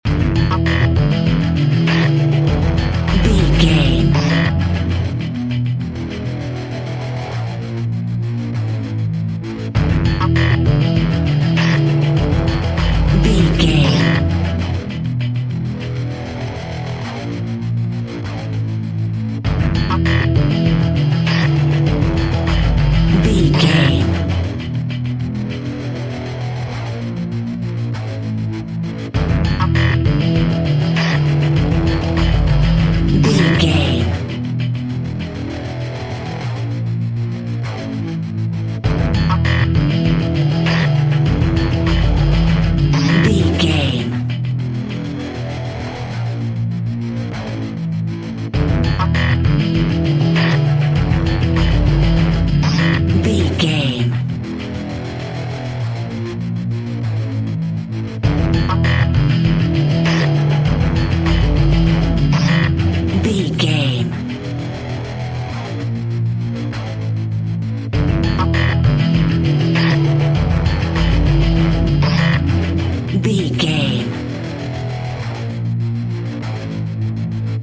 Industrial Music Theme.
Epic / Action
Fast paced
Aeolian/Minor
Fast
groovy
high tech
futuristic
driving
energetic
drums
synthesiser
drum machine
electronic
synth lead
synth bass